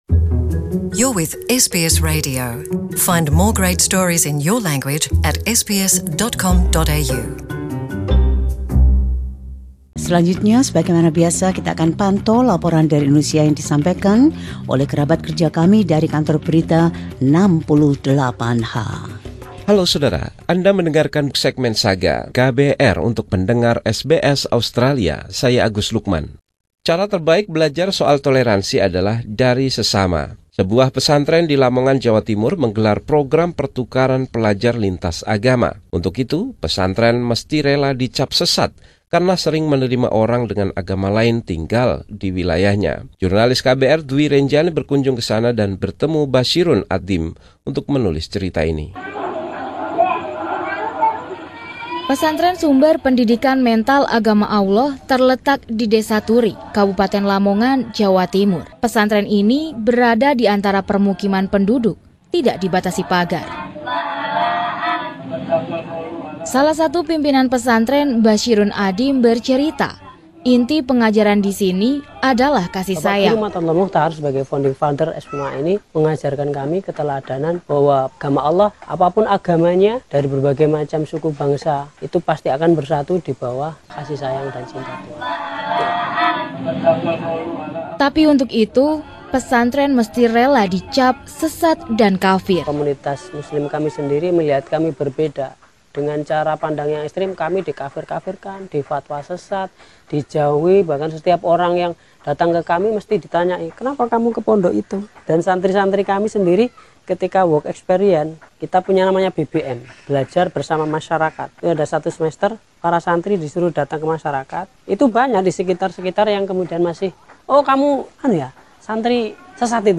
Laporan khusus tim KBR 68H ini menggambarkan sebuah desa yang berfokus untuk mengembangkan saling pengertian dan toleransi.